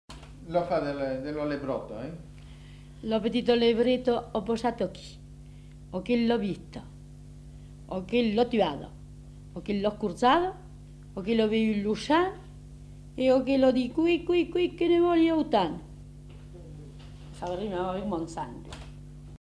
Aire culturelle : Périgord
Lieu : Castels
Genre : forme brève
Effectif : 1
Type de voix : voix de femme
Production du son : récité
Classification : formulette